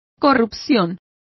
Complete with pronunciation of the translation of pollutions.